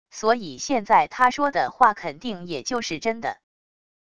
所以现在他说的话肯定也就是真的wav音频生成系统WAV Audio Player